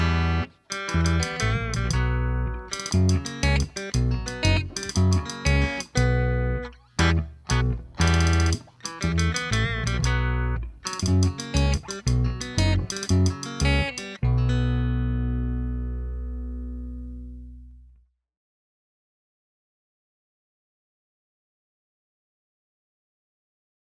No Drums And No Backing Vocals Comedy/Novelty 2:50 Buy £1.50